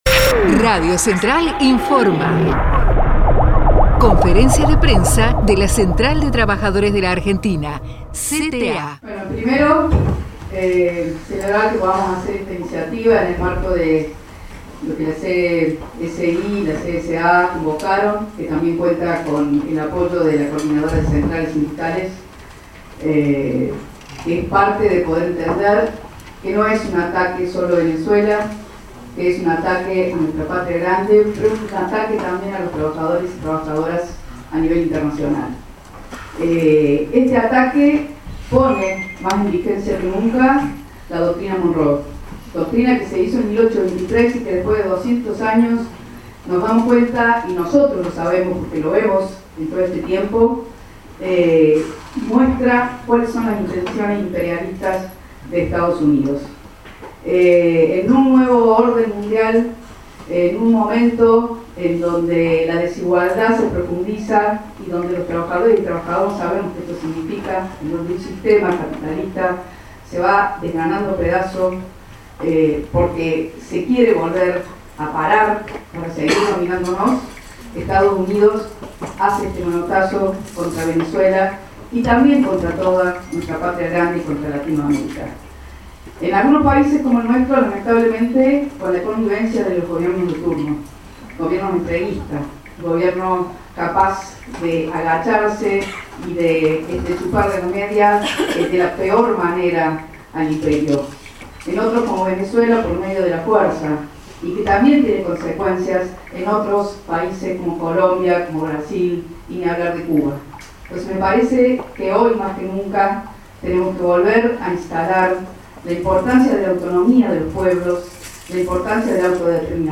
venezuela_conferencia_de_prensa_cta.mp3